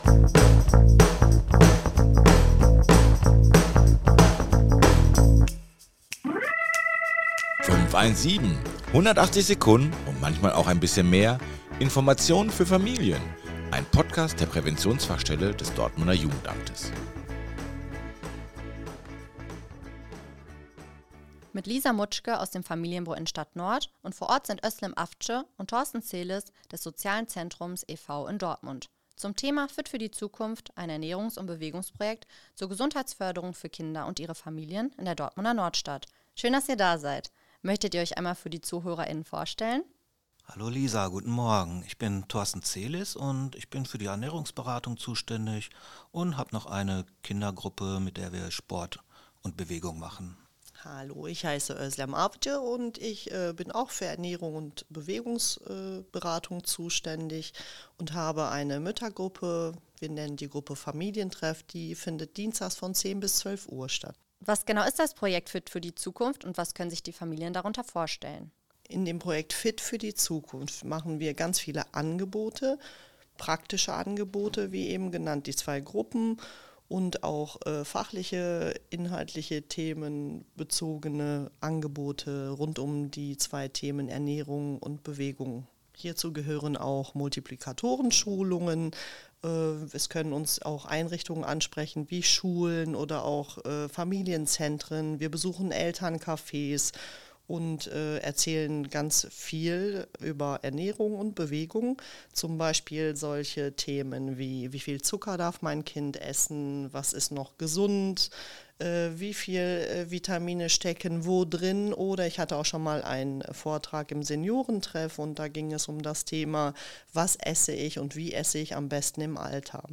In rund 180 Sekunden sind zu Themen wie der Willkommensbesuch, die Arbeit einer Hebamme oder Mehrsprachigkeit interessante Menschen zu Gast, die im Gespräch mit Fachkräften der Präventionsfachstelle Einblicke in ihre Arbeit bzw. ihre Themen geben.